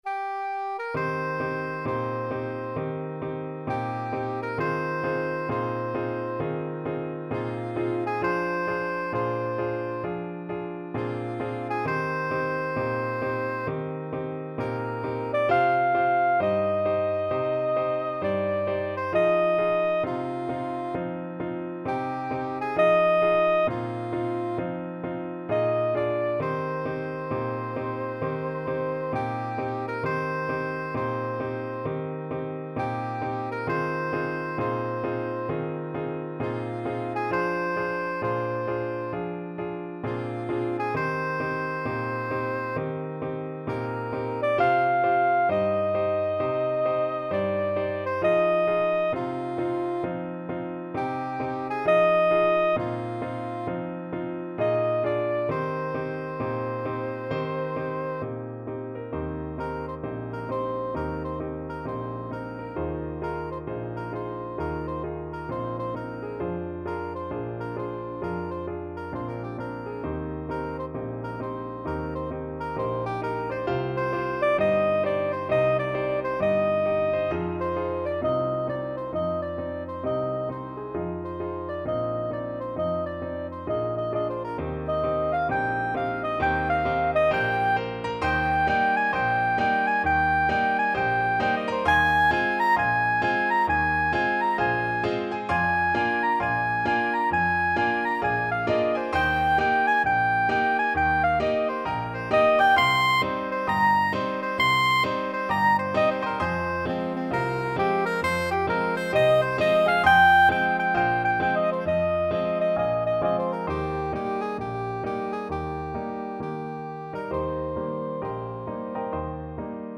Blues Tempo (=66)
Jazz (View more Jazz Soprano Saxophone Music)